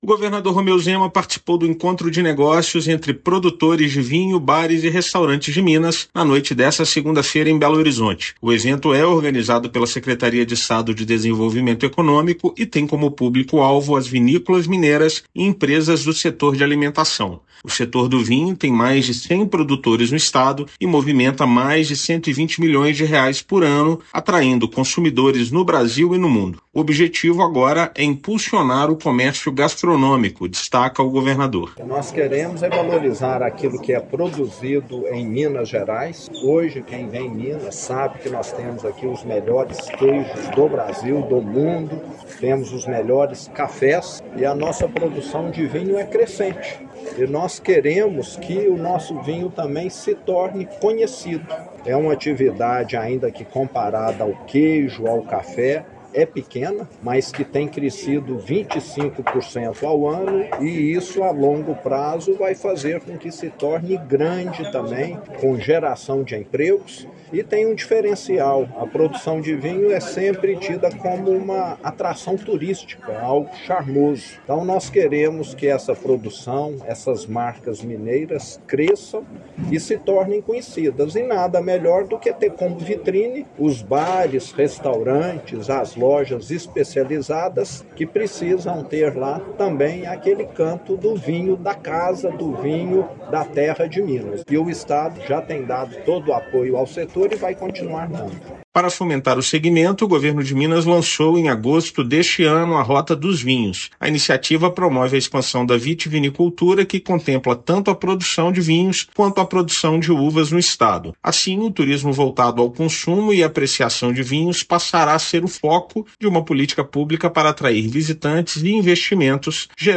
[RÁDIO] Governo realiza Encontro de Negócios entre Produtores de Vinho, Bares e Restaurantes de BH para promover a vinicultura no estado
Segmento conta com mais de cem produtores no estado, que movimentam cerca de R$ 120 milhões anualmente. Ouça matéria de rádio.